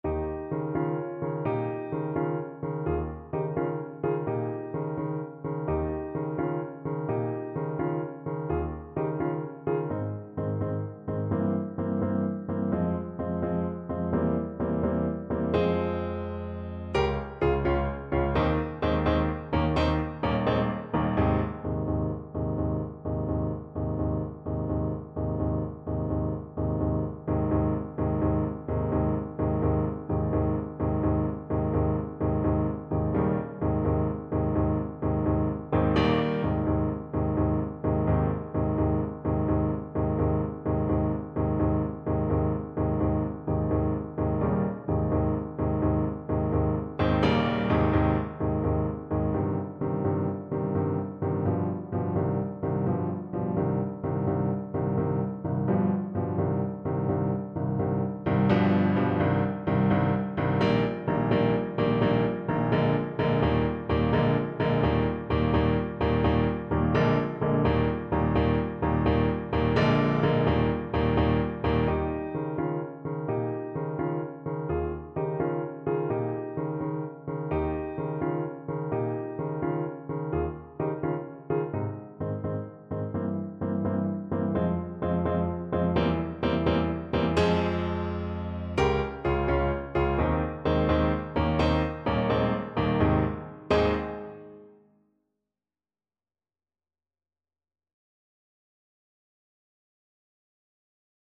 Neapolitan song
6/8 (View more 6/8 Music)
Classical (View more Classical Tenor Saxophone Music)